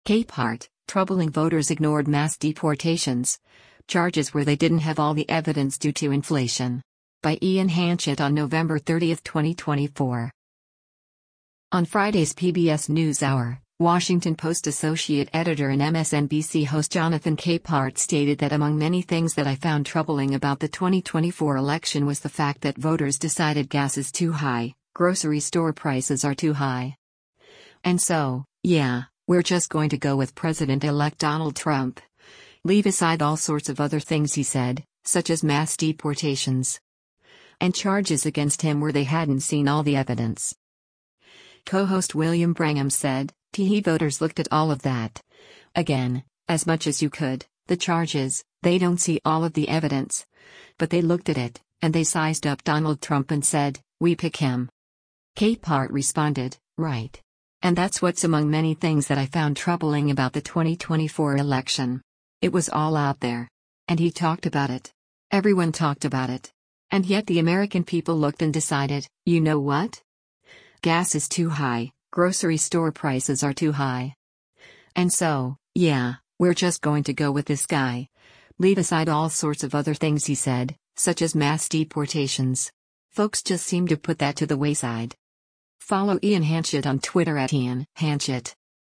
On Friday’s “PBS NewsHour,” Washington Post Associate Editor and MSNBC host Jonathan Capehart stated that “among many things that I found troubling about the 2024 election” was the fact that voters decided “Gas is too high, grocery store prices are too high. And so, yeah, we’re just going to go with” President-Elect Donald Trump, “leave aside all sorts of other things he said, such as mass deportations.” And charges against him where they hadn’t seen all the evidence.